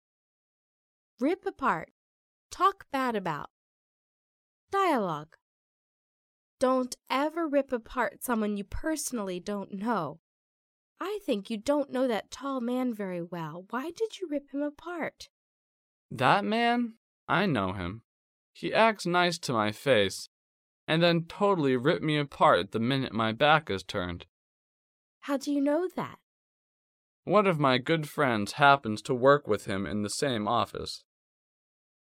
第一，迷你对话